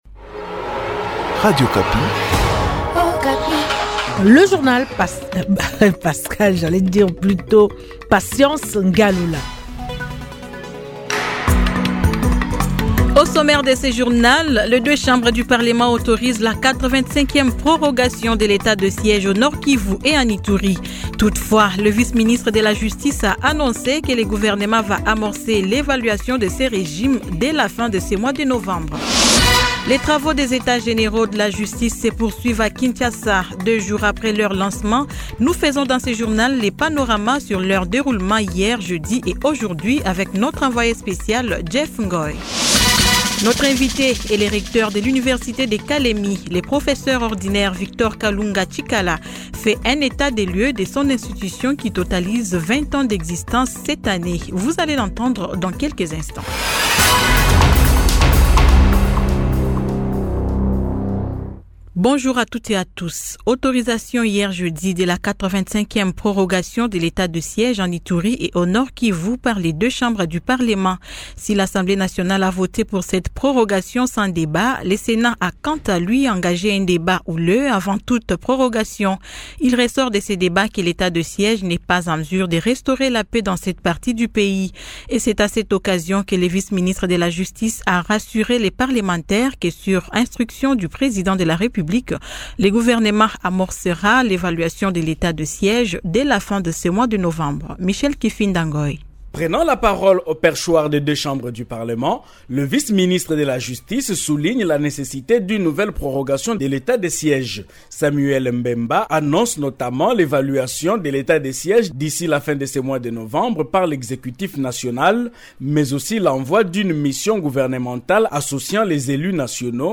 Journal 15H00